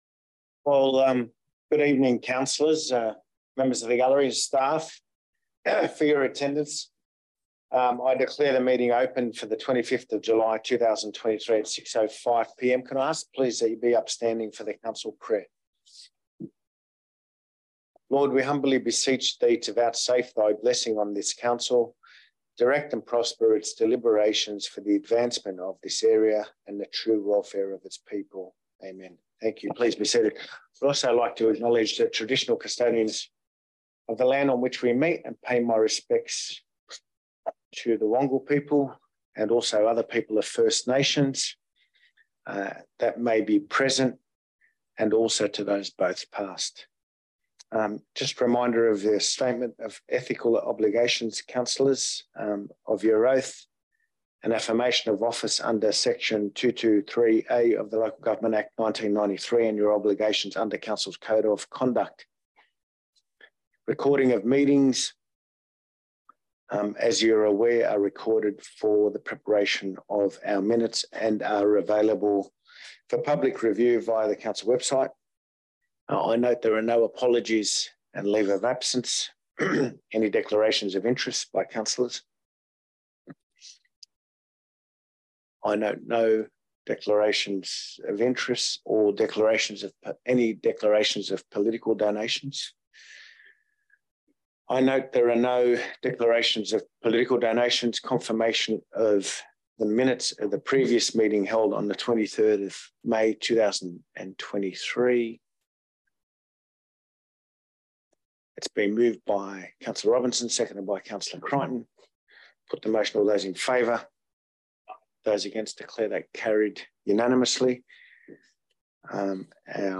Council Meeting - 25 July 2023